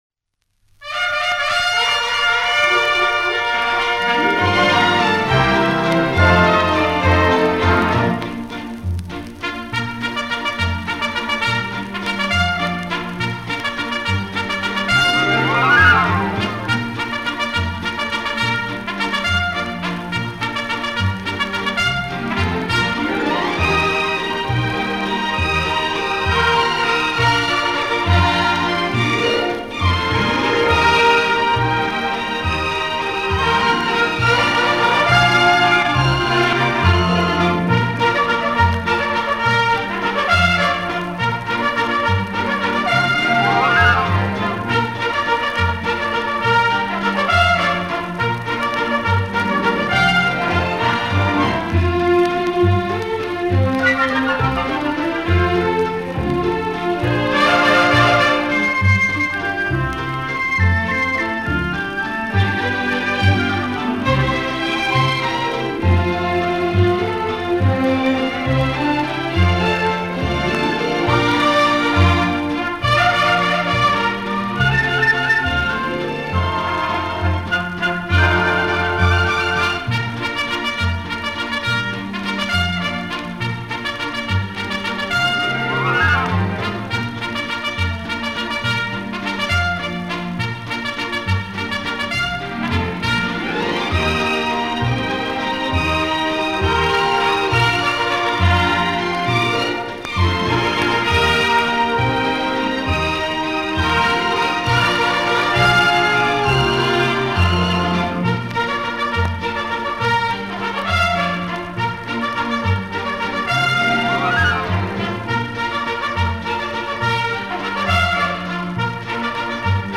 bugle.mp3